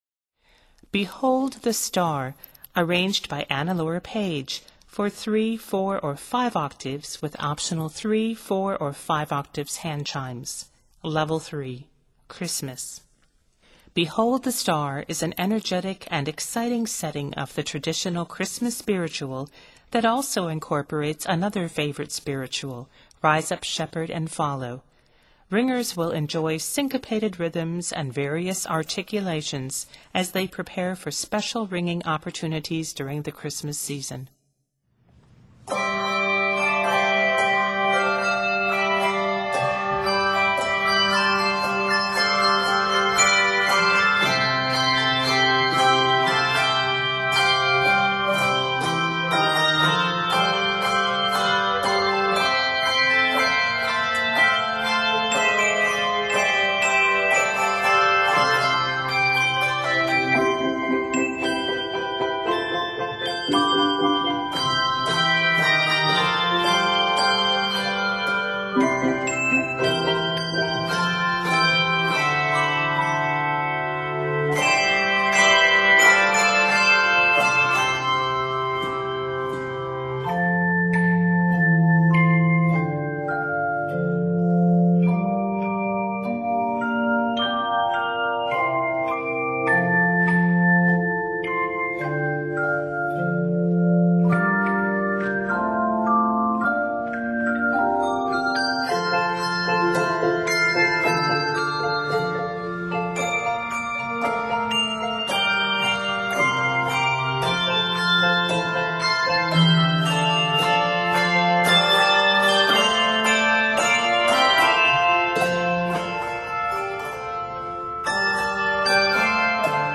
This energetic and exciting setting
It is set in C Major and F Major.